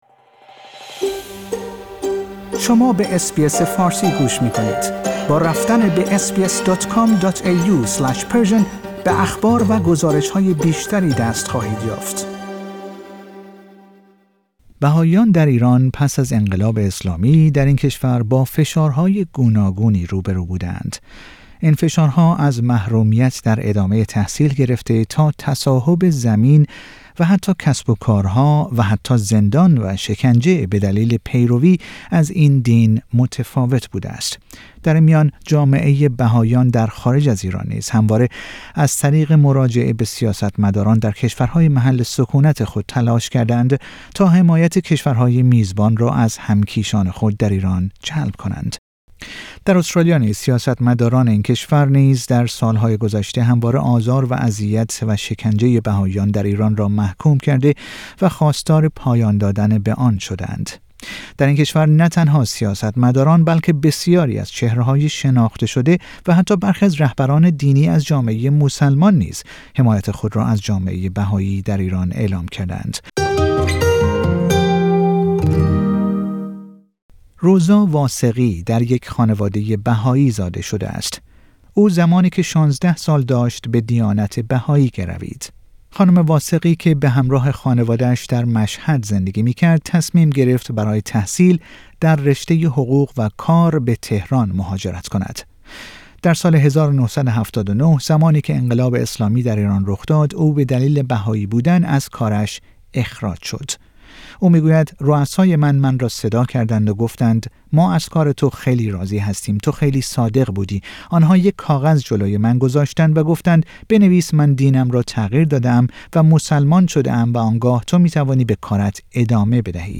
در این گزارش در گفتگو با چهار نفر از جامعه بهایی ساکن استرالیا به تجارب آنها در این زمینه پرداخته ایم و از آنها پرسیده ایم چرا فکر می کنند جمهوری اسلامی با پیروان دیانت بهایی اینگونه رفتار می کند.